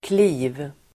Uttal: [kli:v]